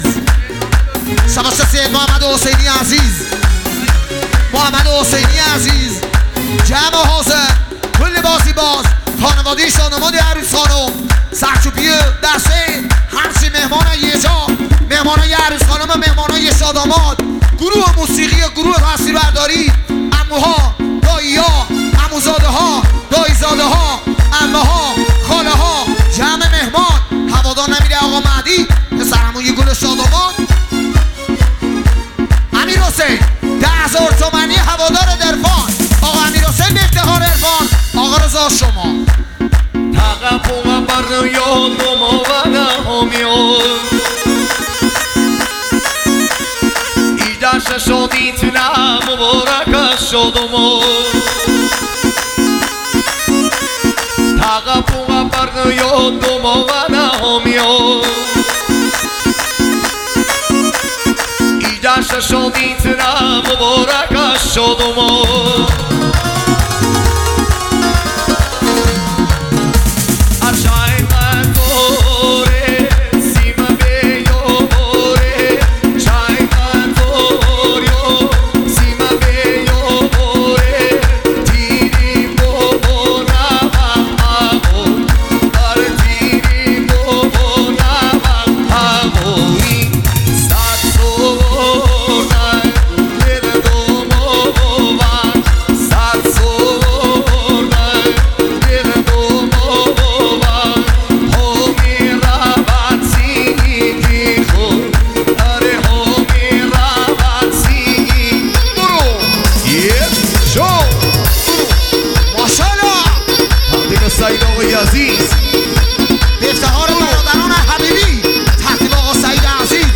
محلی
آهنگ با صدای زن
اهنگ ایرانی